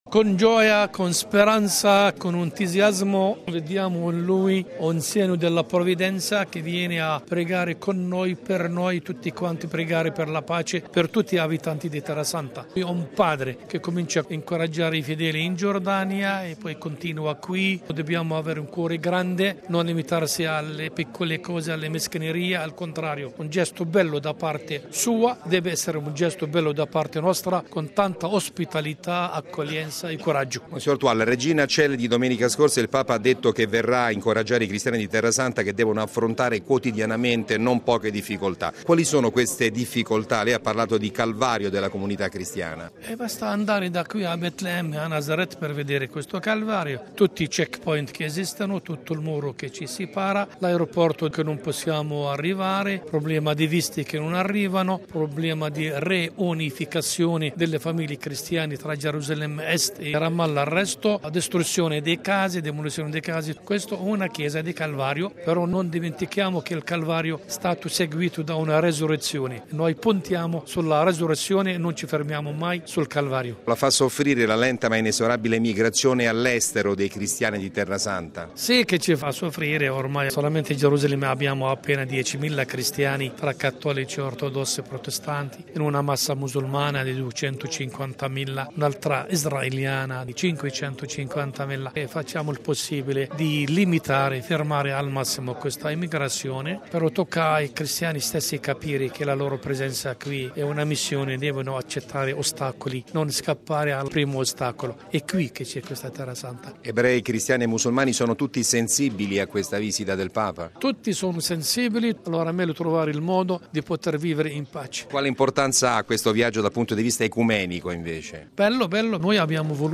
ha raccolto la testimonianza del Patriarca latino della Città Santa, mons. Fouad Twal: